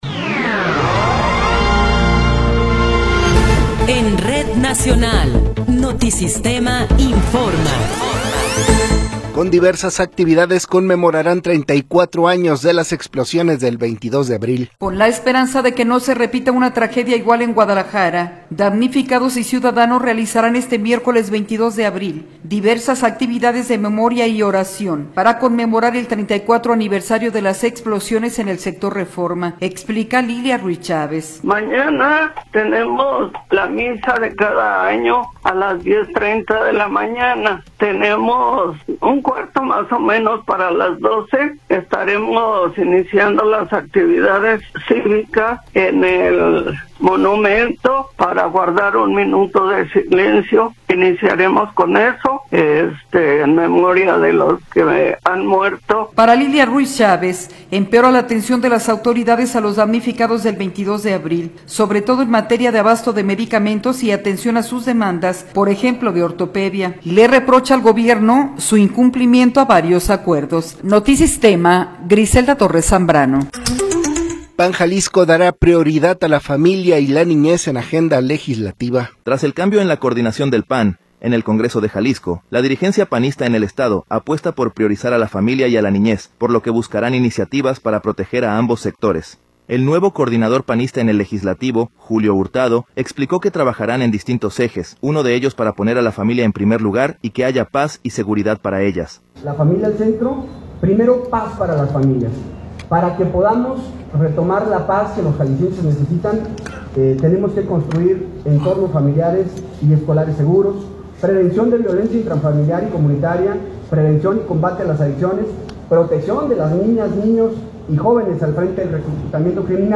Noticiero 13 hrs. – 21 de Abril de 2026